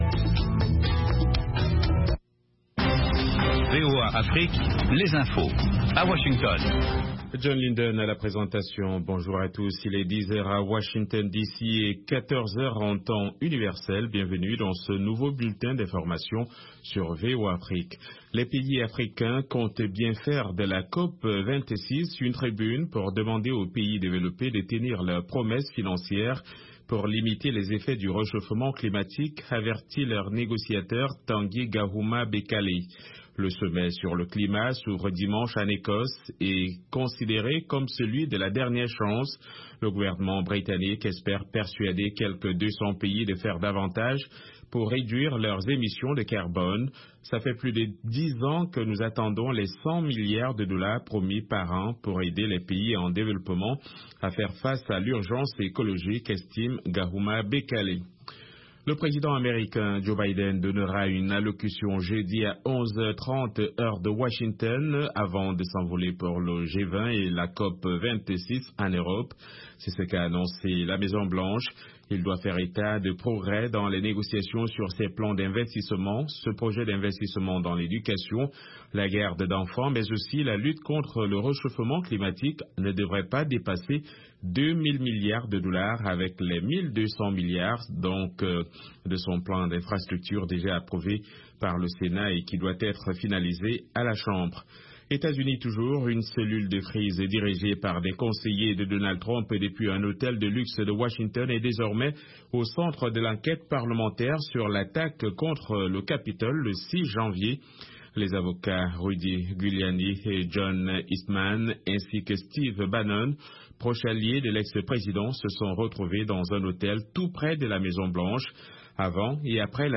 Basango na VOA Lingala